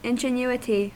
Ääntäminen
US : IPA : [ˌɪn.dʒə.ˈnu.ɪ.ti]